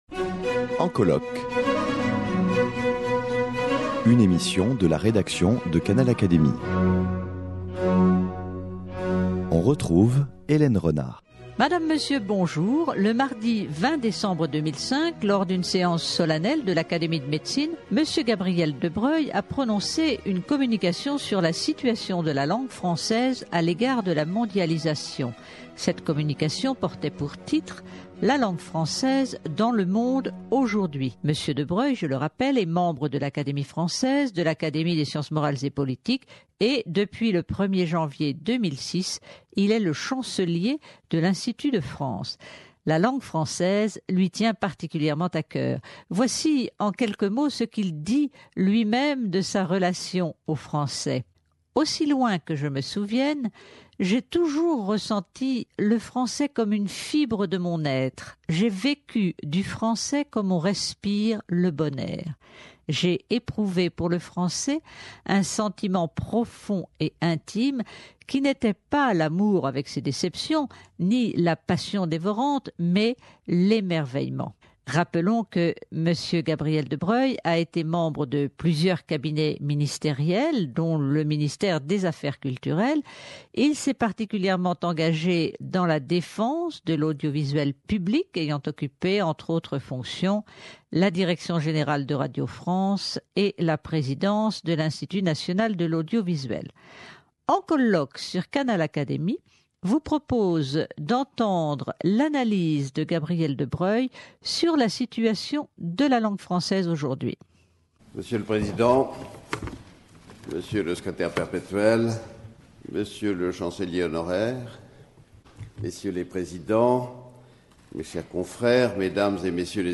Ecoutez la communication de Gabriel de Broglie, chancelier de l’Institut, présentée devant l’Académie nationale de médecine le 20 décembre 2005, consacrée à la situation de la langue française dans le monde, face à la mondialisation.